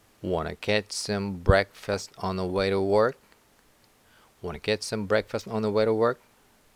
小文字のところは、弱く速く発音します。